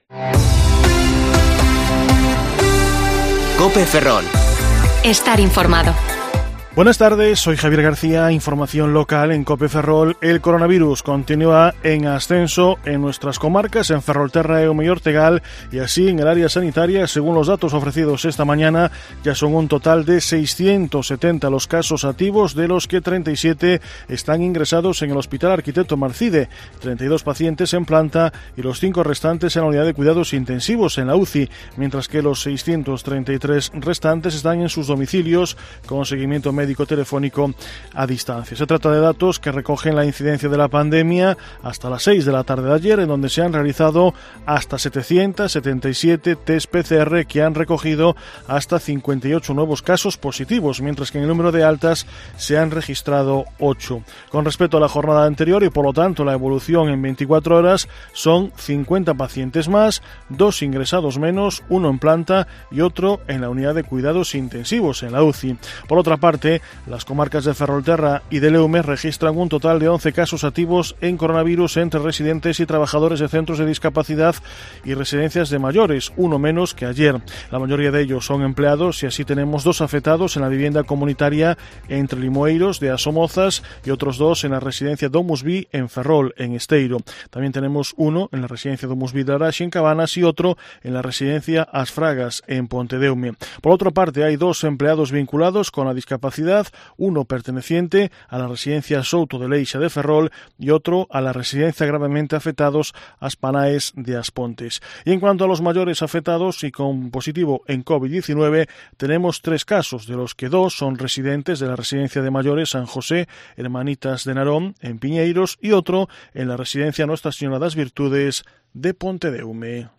Informativo Mediodía COPE Ferrol 29/10/2020 (De 14,20 a 14,30 horas)